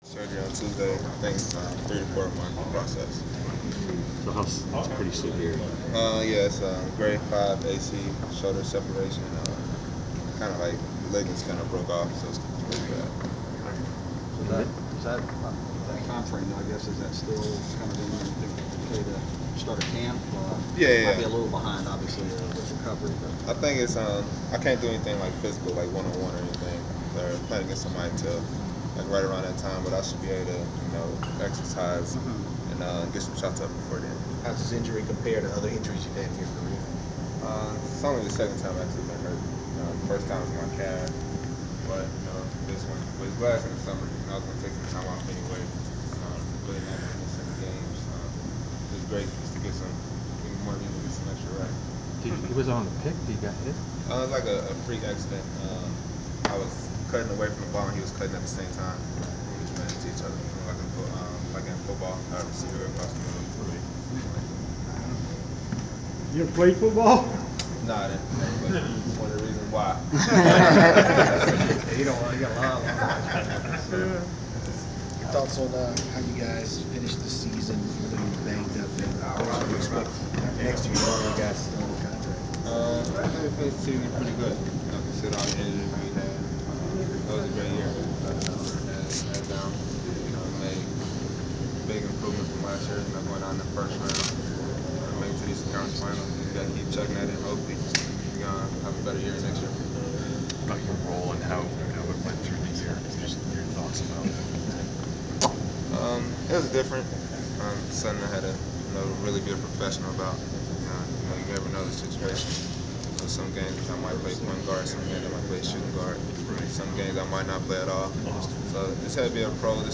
Inside the Inquirer: Postseason presser with Atlanta Hawk Shelvin Mack
The Sports Inquirer attended the media presser of Atlanta Hawks guard Shelvin Mack following the conclusion of his team’s season. Topics included Mack’s timetable for returning from shoulder surgery and increased playing time during the season.